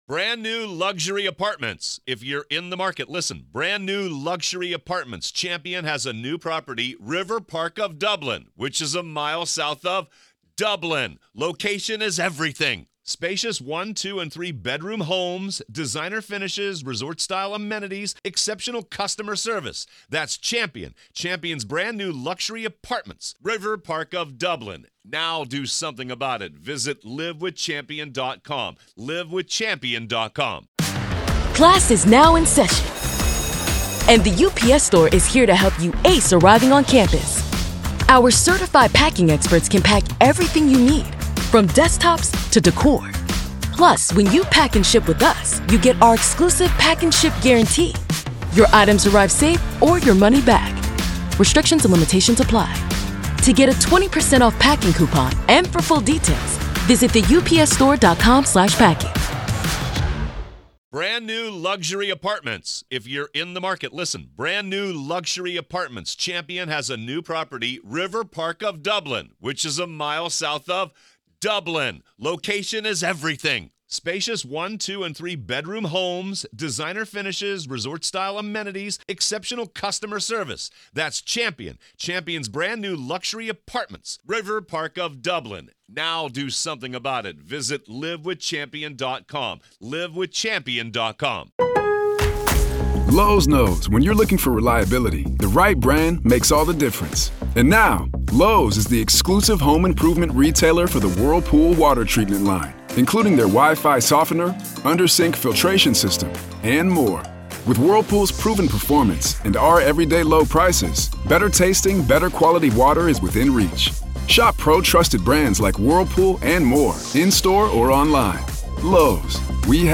Former prosecutor